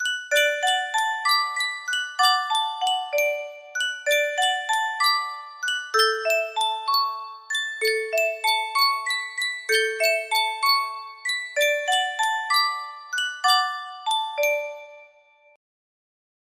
Yunsheng Music Box - I Went to the Animal Fair 4761 music box melody
Full range 60